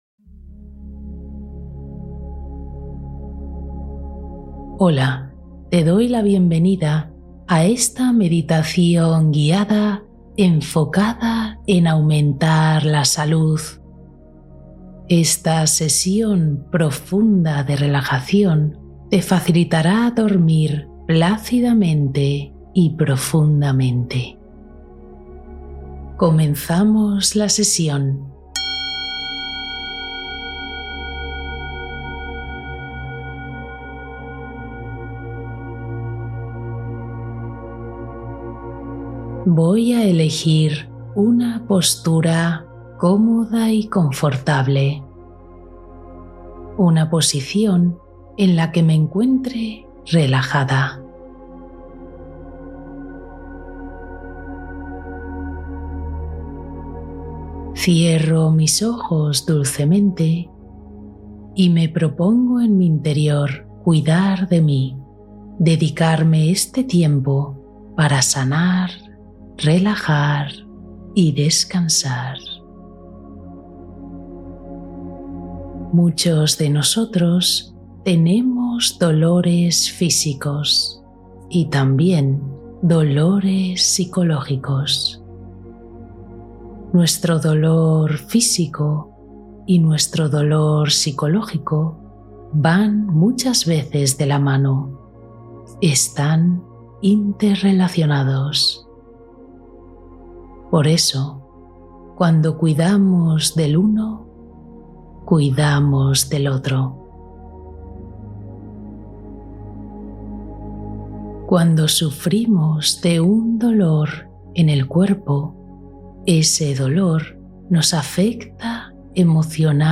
Duerme feliz, libera tensiones y sana cuerpo y mente con esta meditación profunda